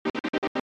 La señal en la pista metálica suena como un chirrido.